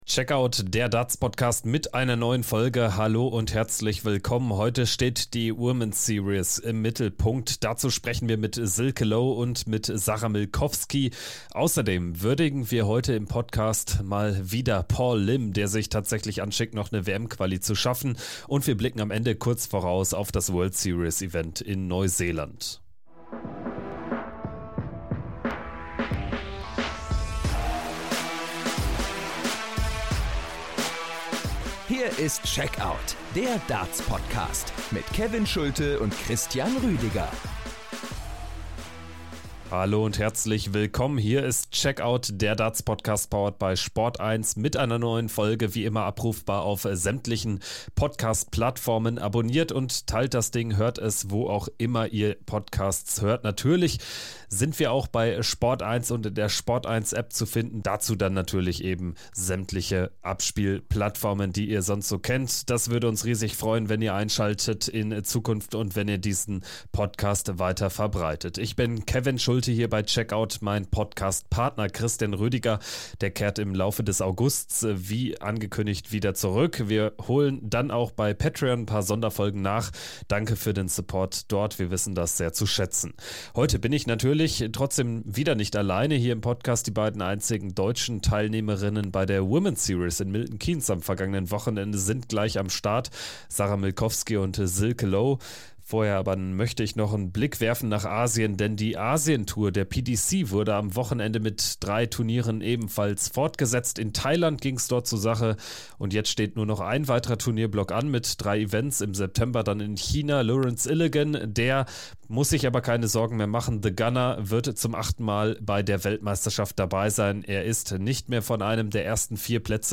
Doppel-Interview